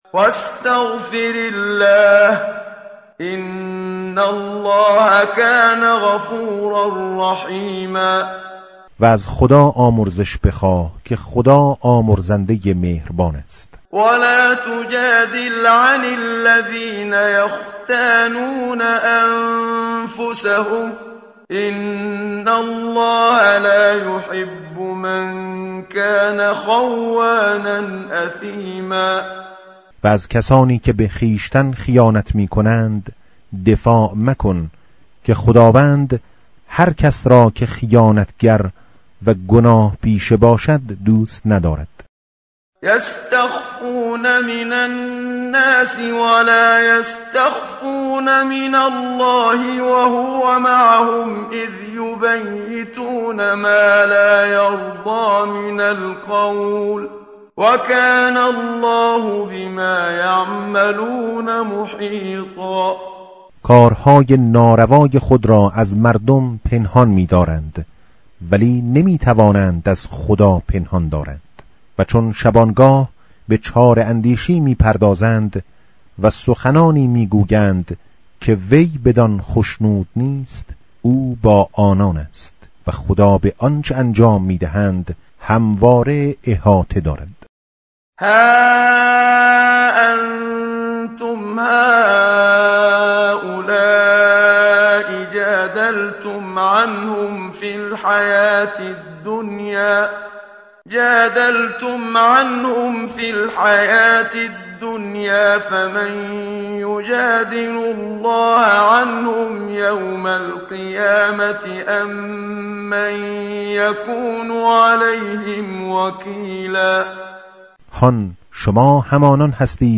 متن قرآن همراه باتلاوت قرآن و ترجمه
tartil_menshavi va tarjome_Page_096.mp3